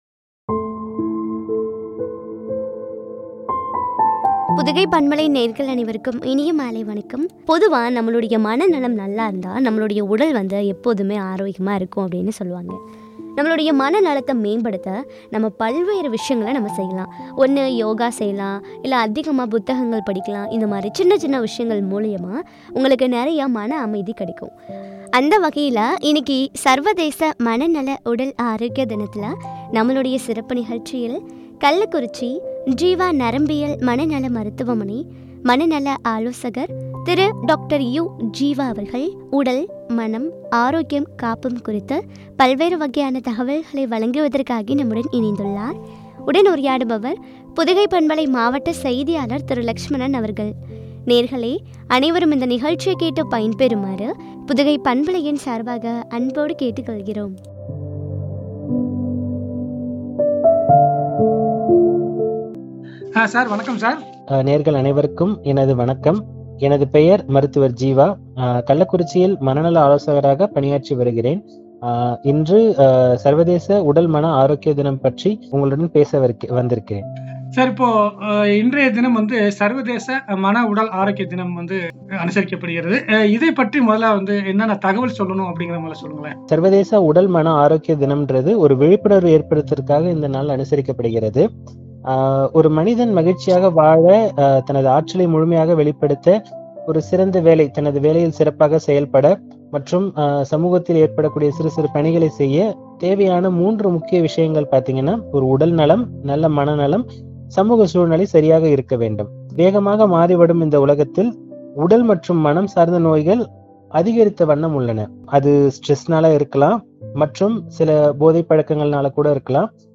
காப்போம் ” குறித்து வழங்கிய உரையாடல்.